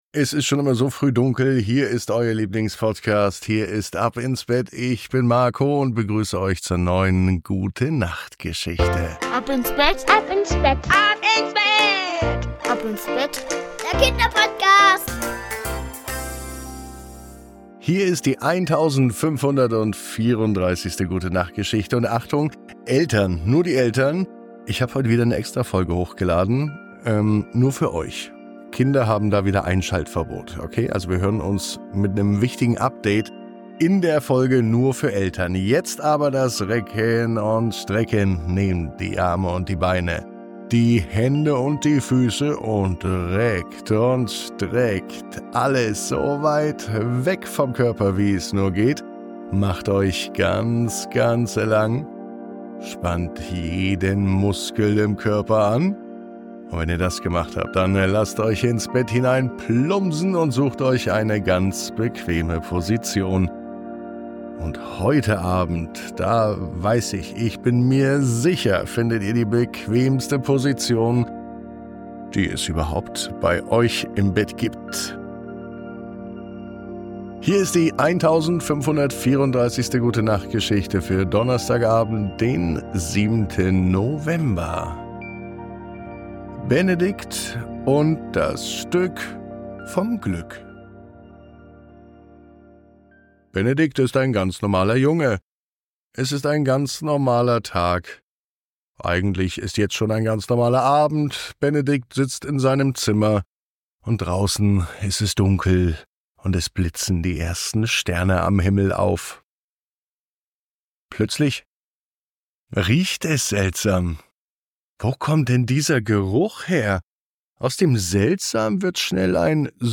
Die Gute Nacht Geschichte für Donnerstag